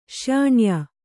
♪ śyāṇyā